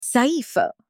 (به فتحه روی حرف “س” و سکون روی “ی” و فتحه روی “ف” تلفظ می‌شود: سَيْف )
scimitar-in-arabic.mp3